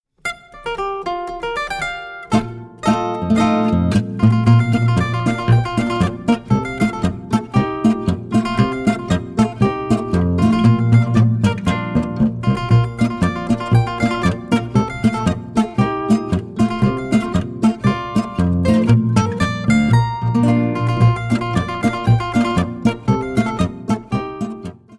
Stücke mit Zither & Kontragitarre